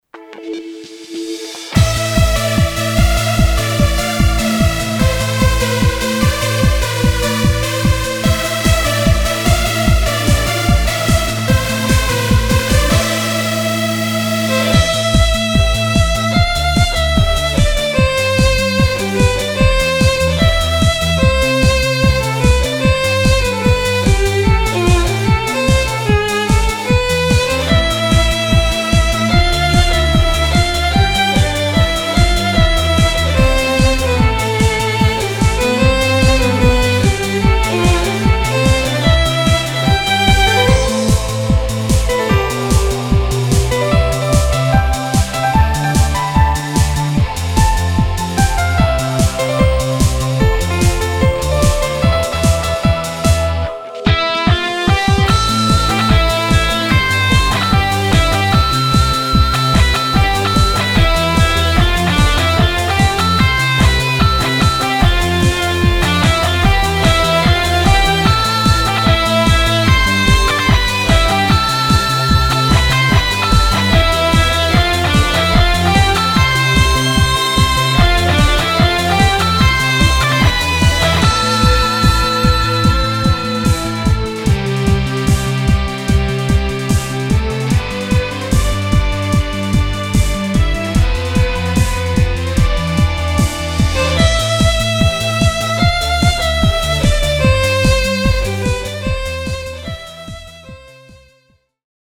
フリーBGM フィールド・ダンジョン フィールド探索・疾走感
フェードアウト版のmp3を、こちらのページにて無料で配布しています。